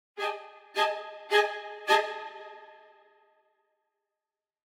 warning.ogg